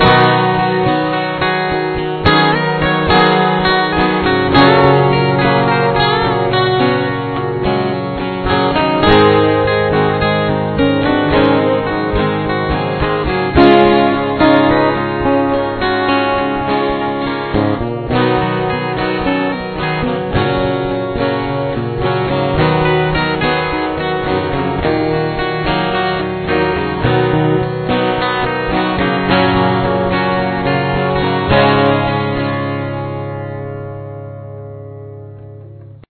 Solo # 1
This solo is pretty easy.
rhythm guitars, which repeat the same concept.